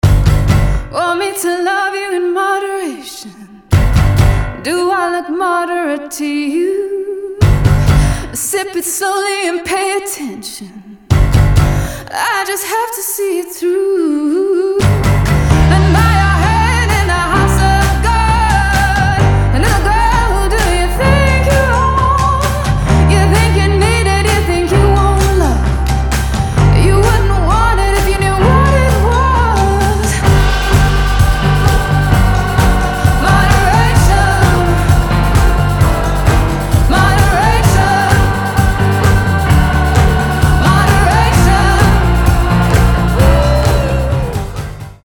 • Качество: 320, Stereo
ритмичные
чувственные
красивый женский вокал
alternative
baroque pop
art pop